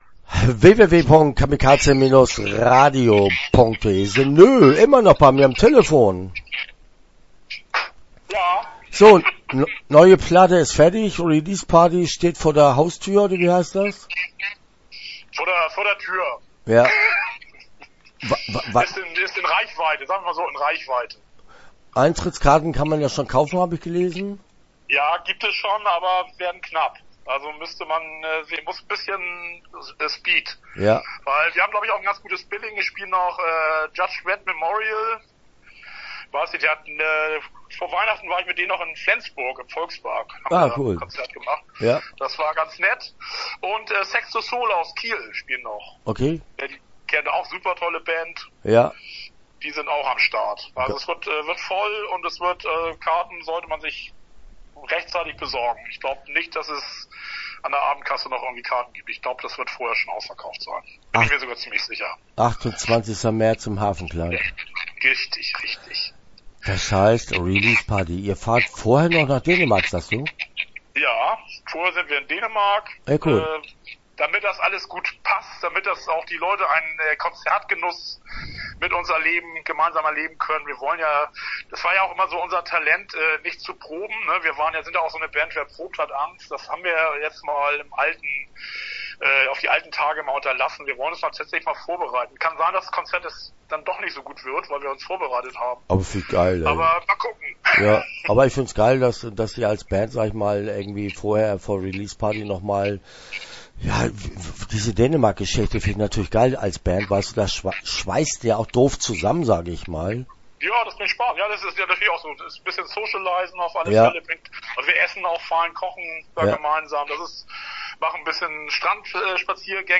Start » Interviews » The NØ